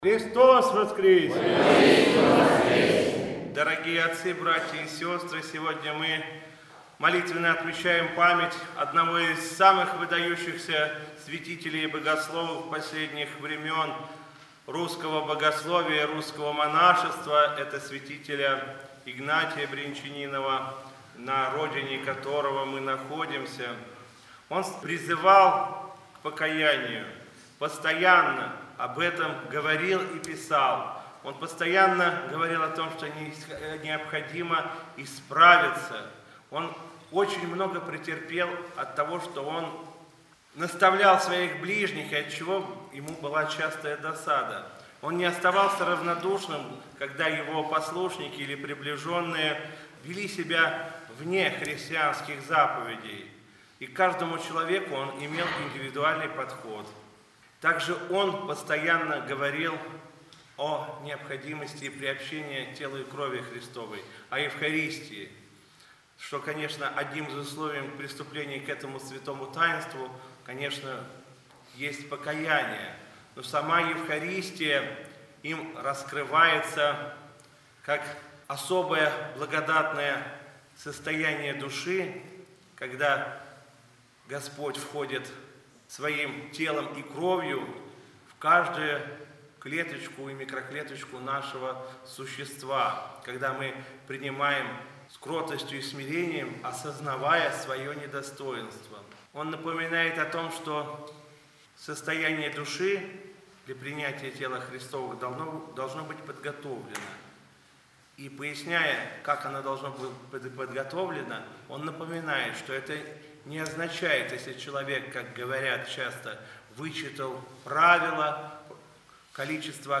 Митрополит Игнатий.
Слово после Литургии в Покровском храме усадьбы Брянчаниновых